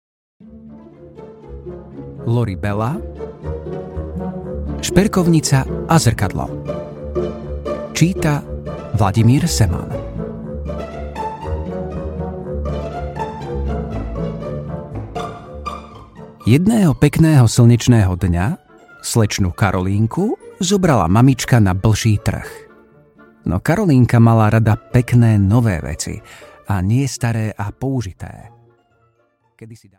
Šperkovnica a zrkadlo audiokniha
Ukázka z knihy